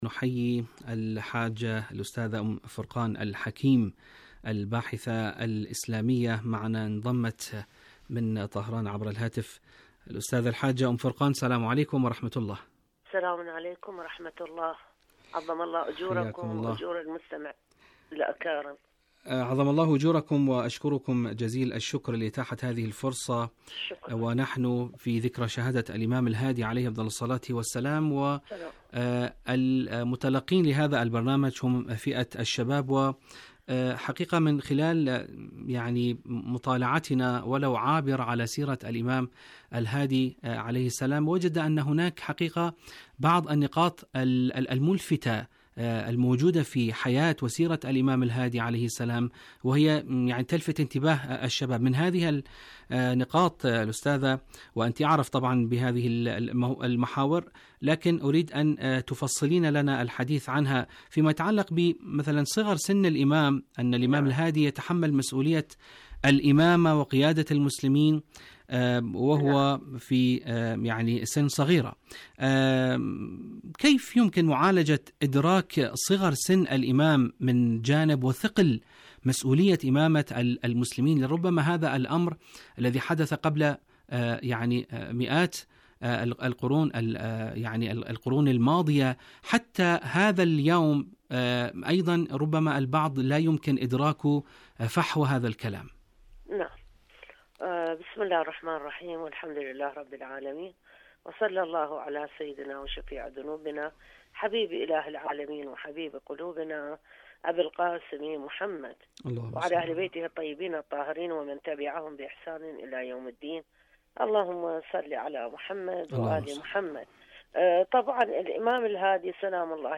مشاركة هاتفية